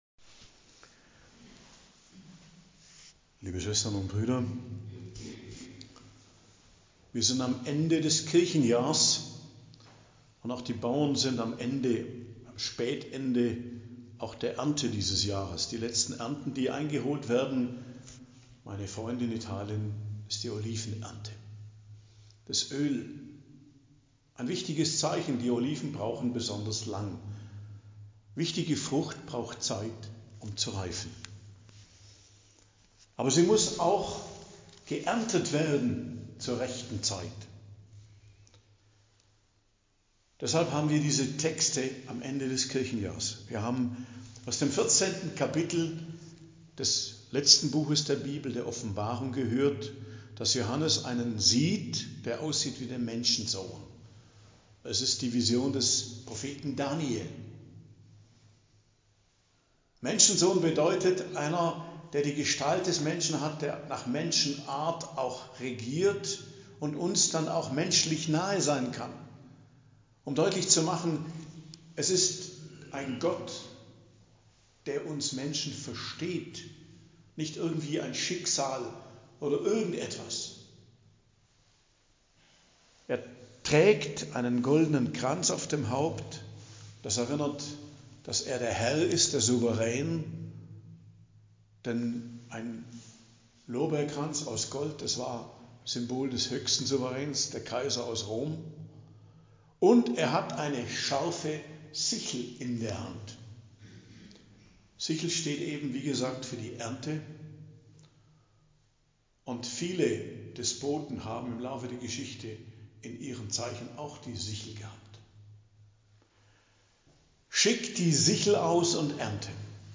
Predigt am Dienstag der 34. Woche i.J. 26.11.2024